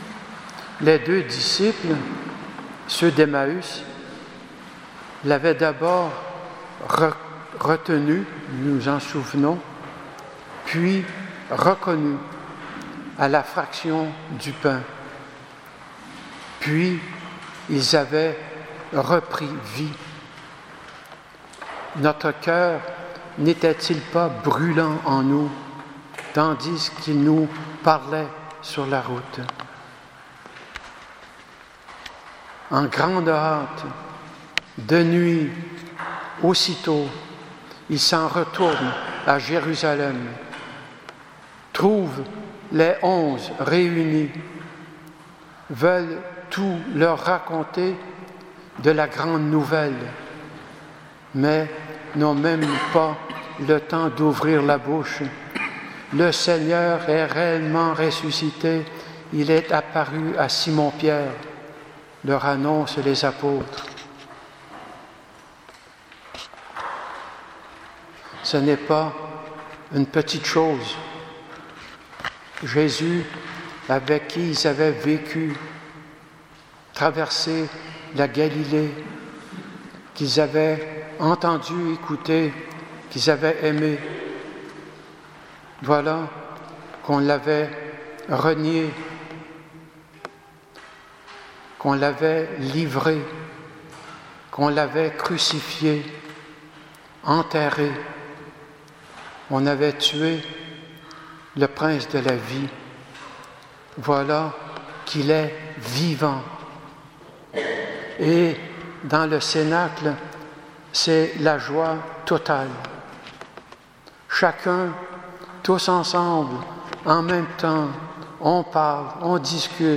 Homélie du 3ème dimanche de Pâques